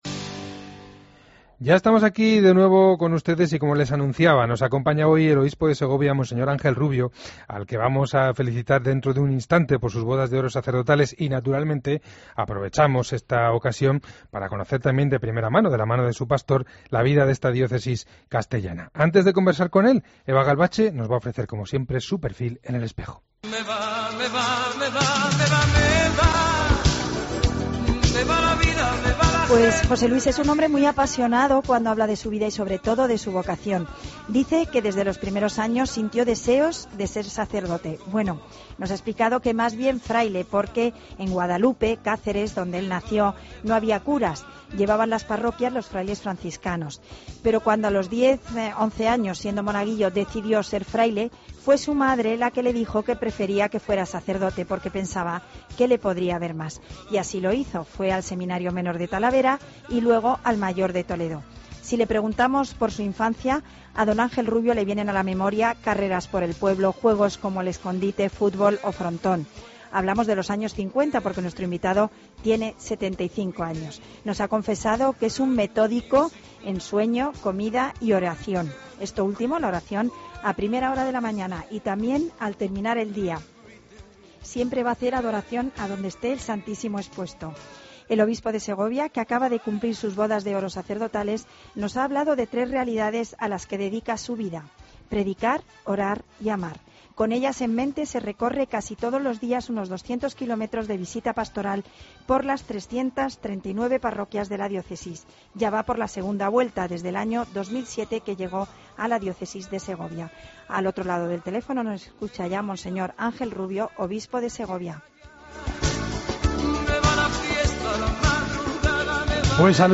AUDIO: Escucha la entrevista completa a monseñor Ángel Rubio en 'El Espejo'